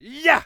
EXCLAMATION_Male_B_Yeah_mono.wav